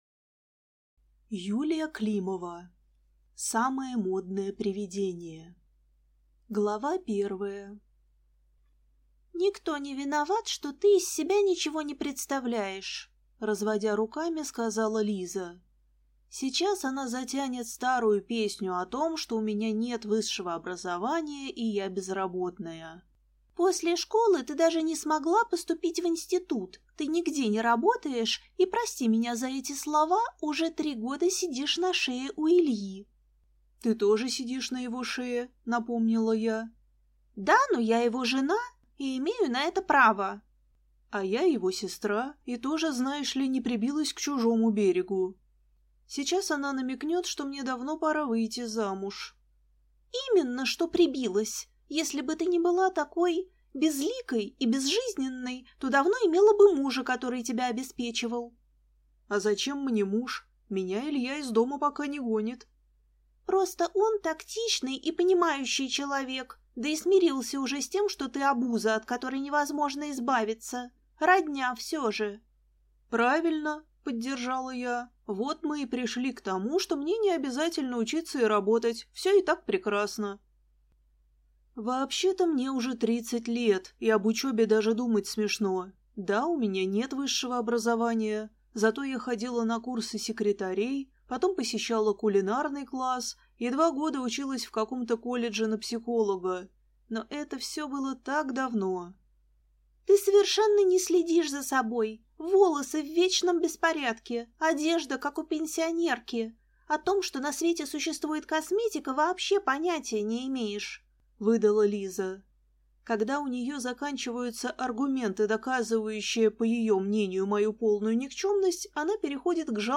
Аудиокнига Самое модное привидение | Библиотека аудиокниг